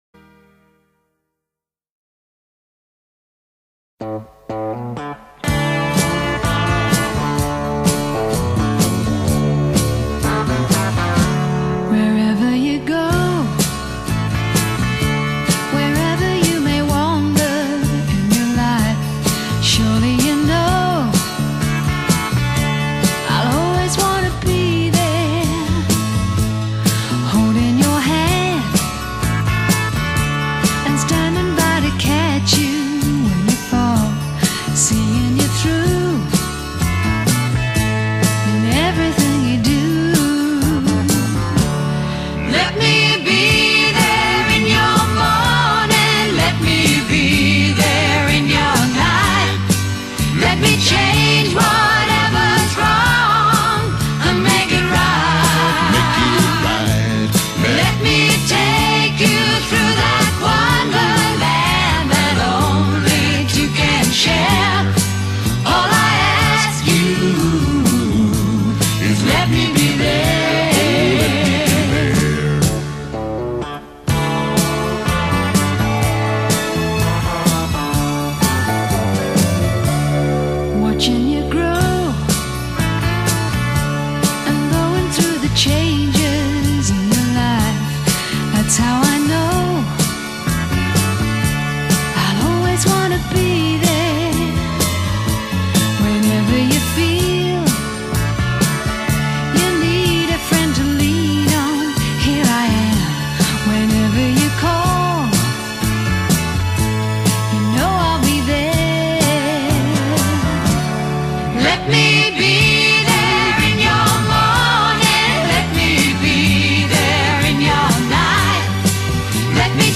The country-influenced song
bass vocal harmony